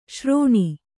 ♪ śrōṇi